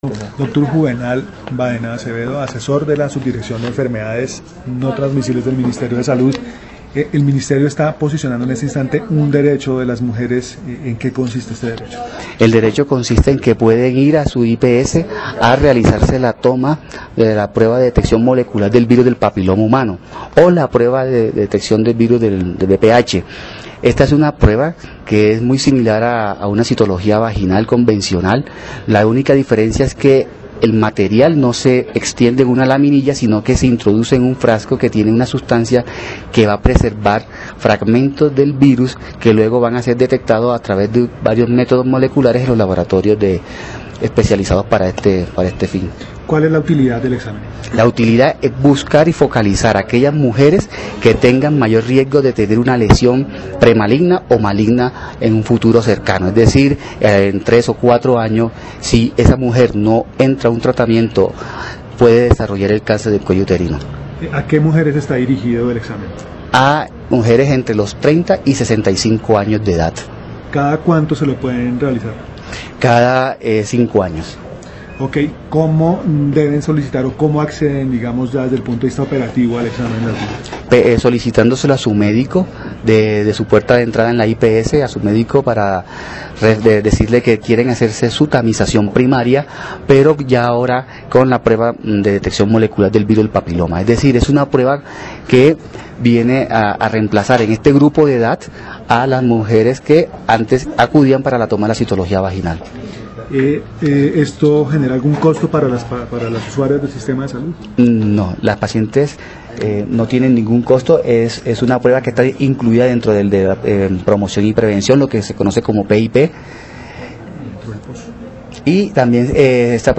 Audio: declaraciones